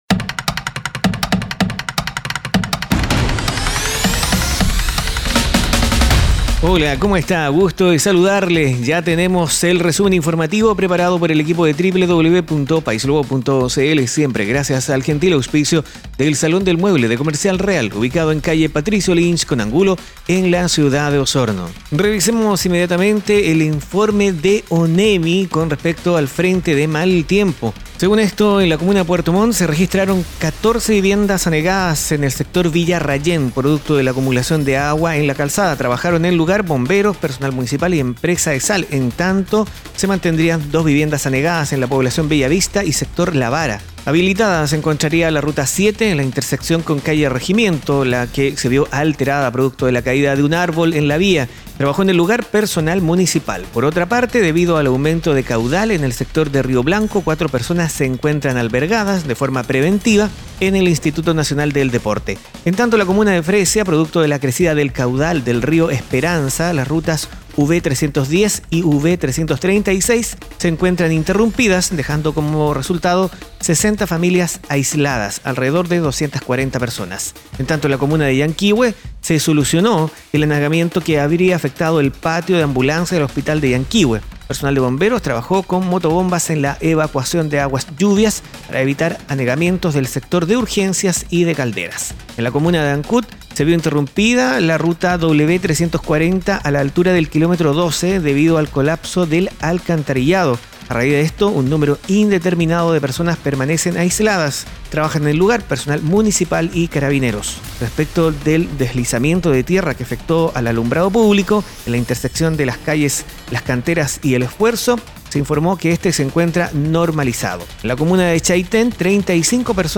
Resumen Informativo - Jueves 02 de mayo 2019